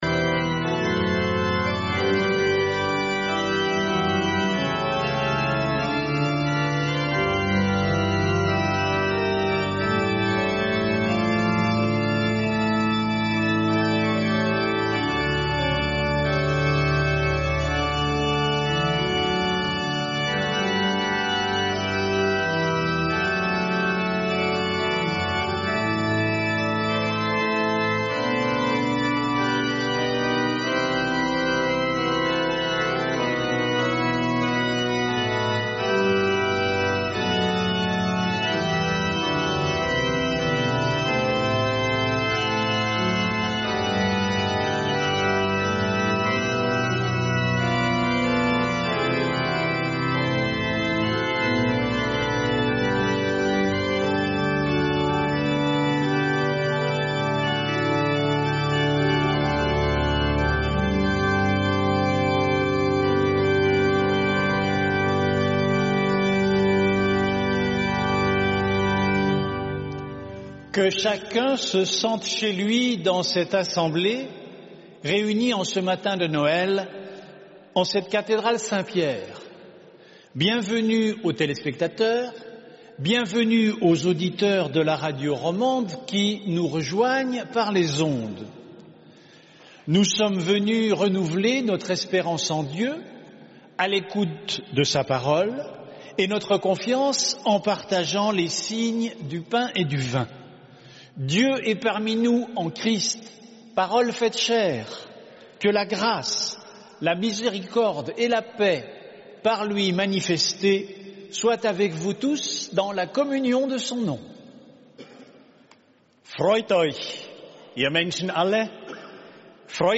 Un culte retransmis en direct sur RTS Un en Eurovision et sur Espace 2.